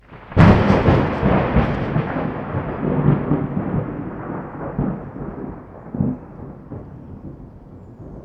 thunder-7.mp3